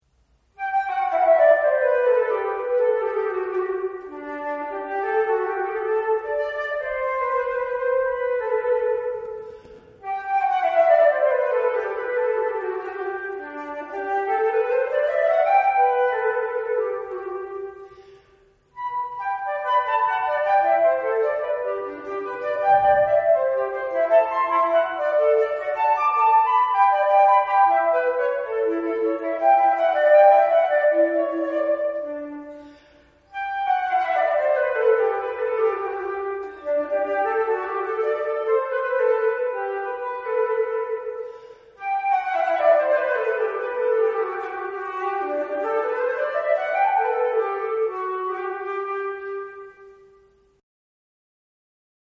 Flötenmusik
Ich habe eine Schwäche für Barockmusik und meine Holzflöte.
Hier ein paar Hörproben in nicht perfekten Aufnahmen: